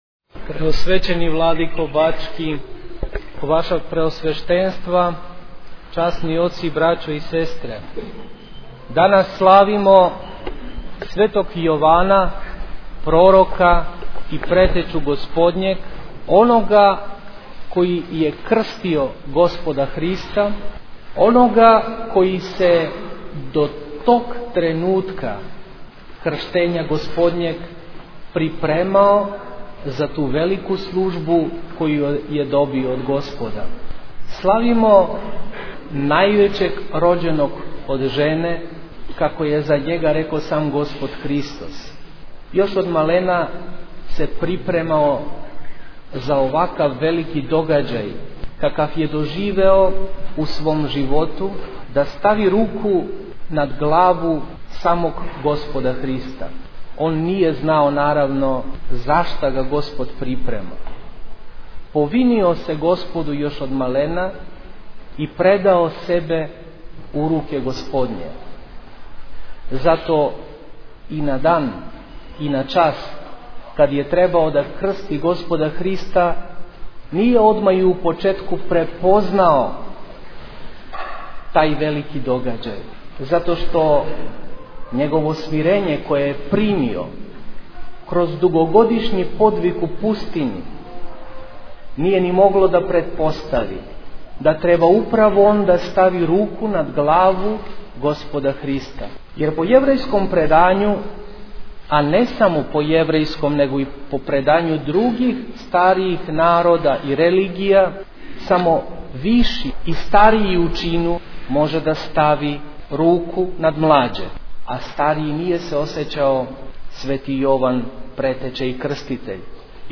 Пригодном беседом верном народу се обратио Архиепископ Јован, честитајући свима празник, а епископима Иринеју и Порфирију крсну славу.
Беседа Архиепископа охридског и митрополита скопског Господина Јована